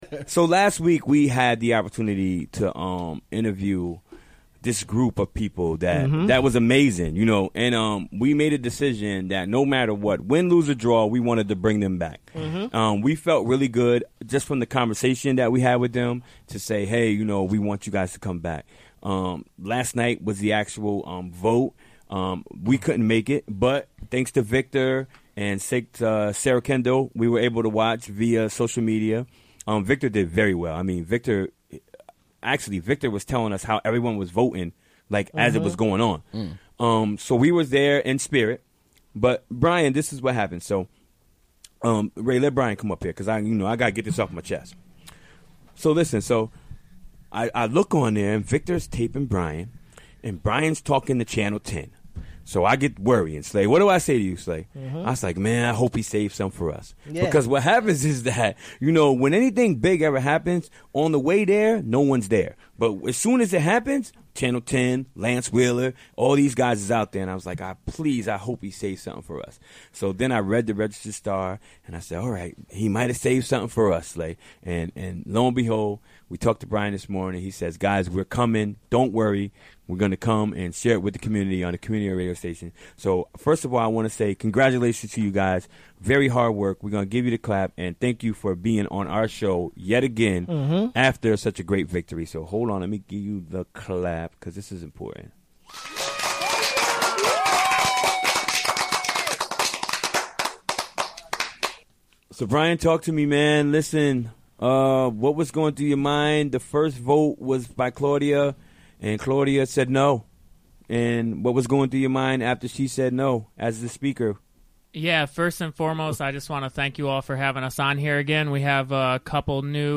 Recorded during the WGXC Afternoon Show Wednesday, March 22, 2017.